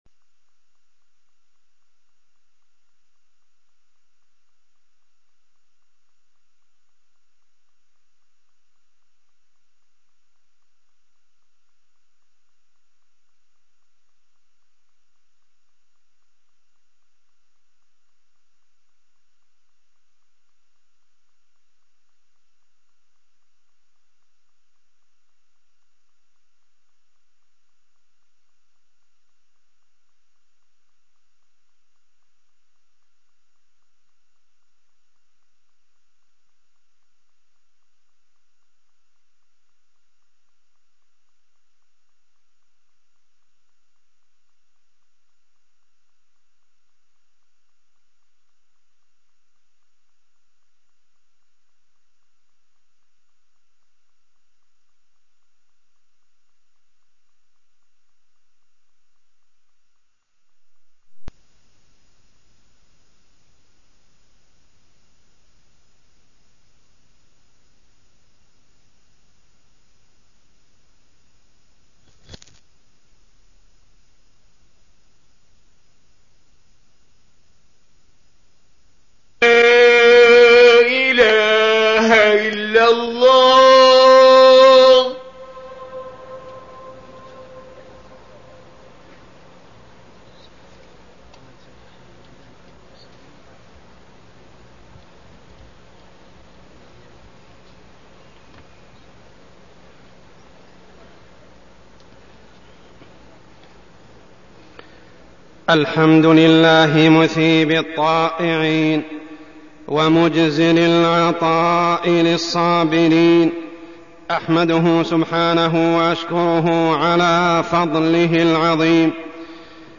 تاريخ النشر ١٩ جمادى الآخرة ١٤١٩ هـ المكان: المسجد الحرام الشيخ: عمر السبيل عمر السبيل الصبر